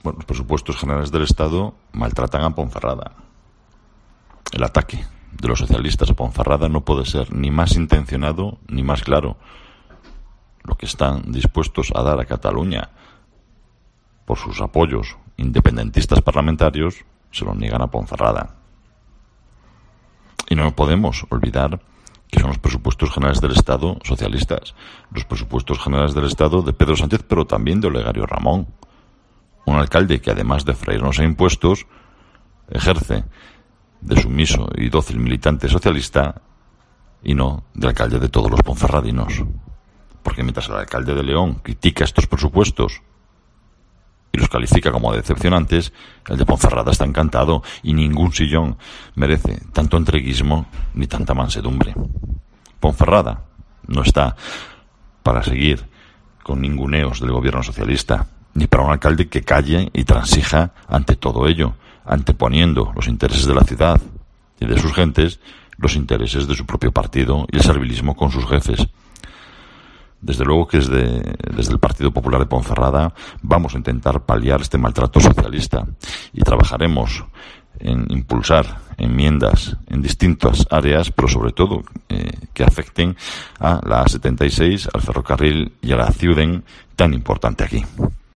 Escucha aquí a Marco Morala, portavoz de los populares en la capital berciana